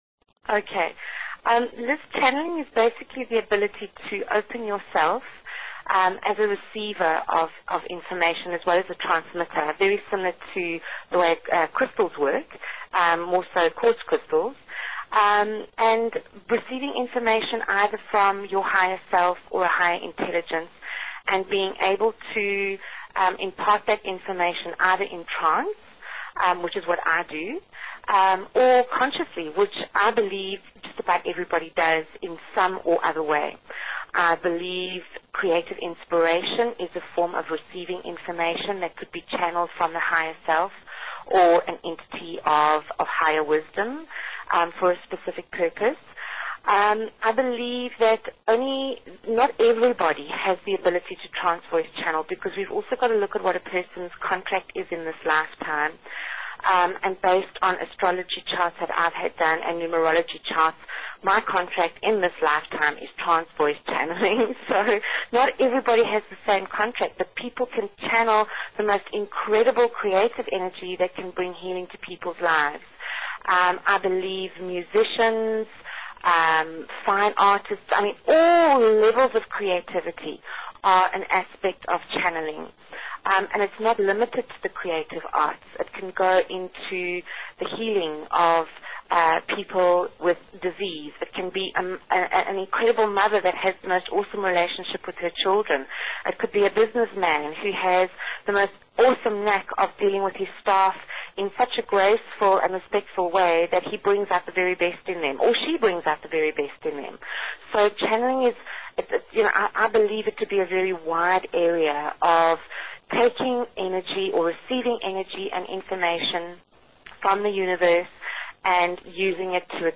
Talk Show Episode, Audio Podcast, Otherware and Courtesy of BBS Radio on , show guests , about , categorized as